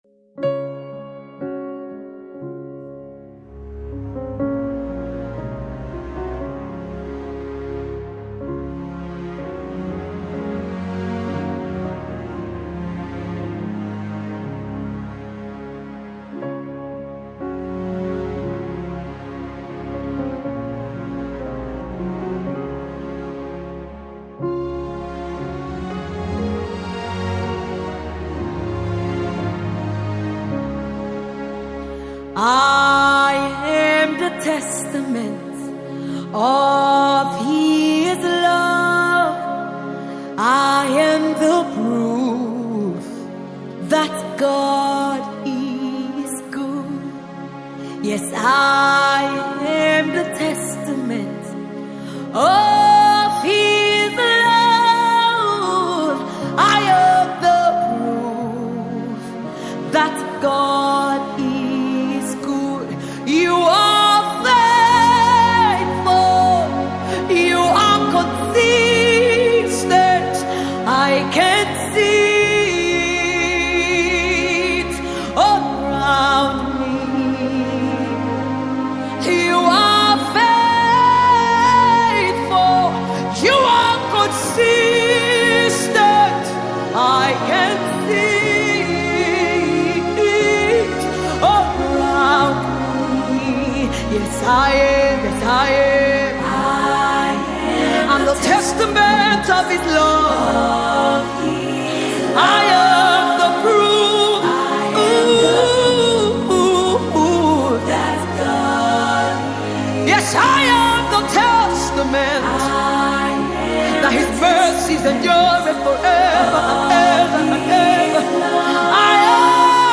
this brand new and soulful sound